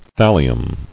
[thal·li·um]